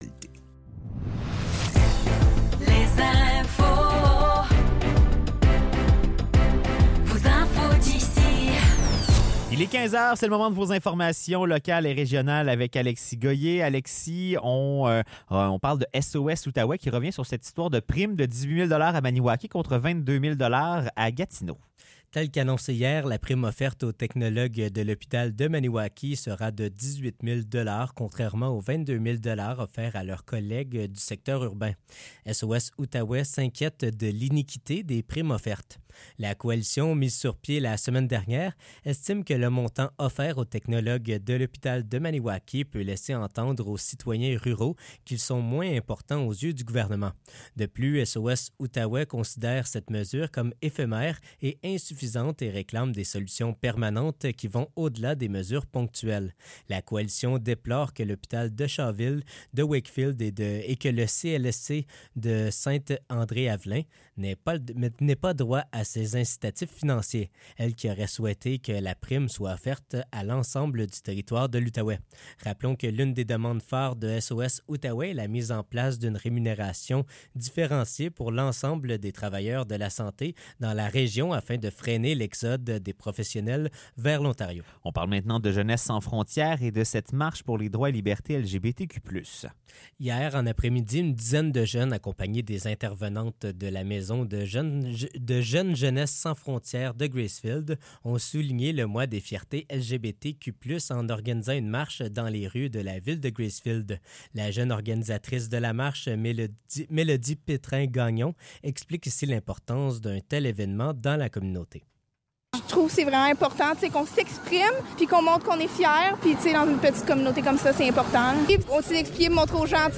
Nouvelles locales - 14 juin 2024 - 15 h